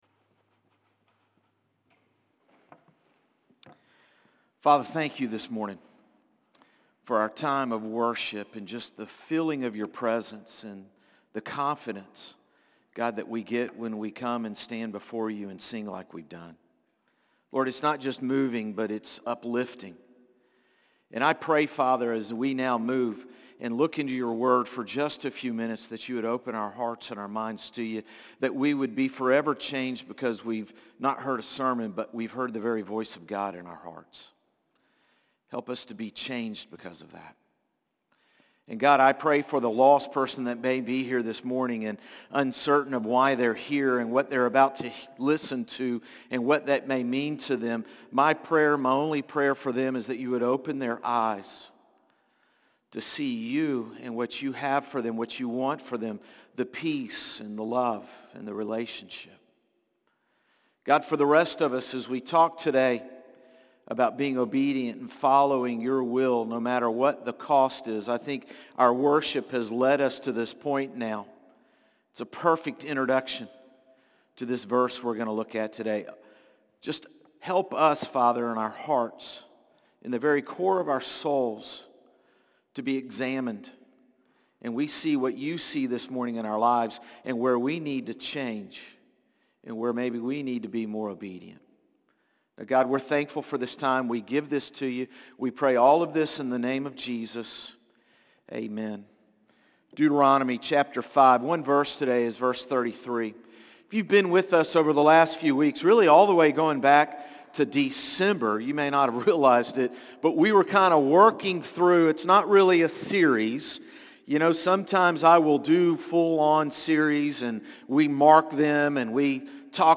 Sermons - Concord Baptist Church
Morning-Service-3-9-25.mp3